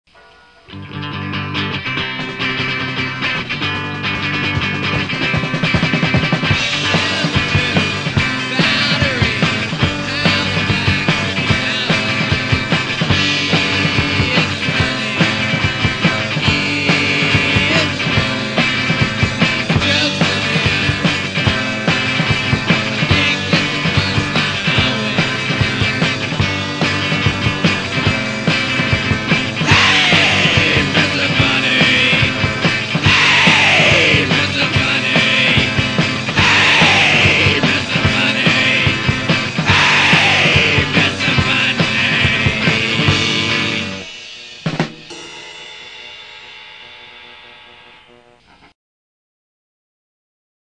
Classic 1990s Lehigh Valley punk
punk rock See all items with this value
cassette